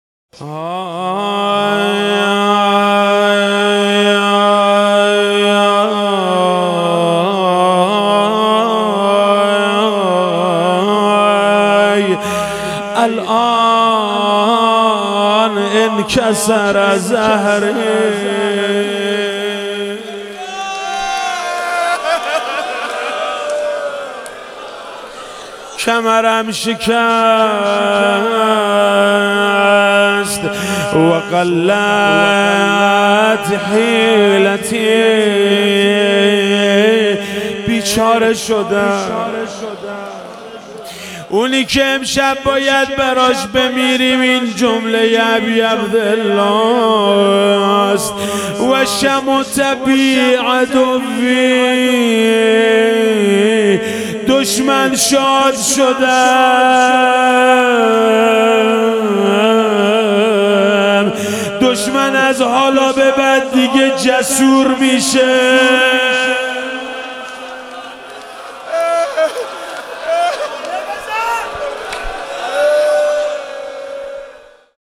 شب تاسوعا محرم 97 - روضه - کمرم شکست بیچاره شدم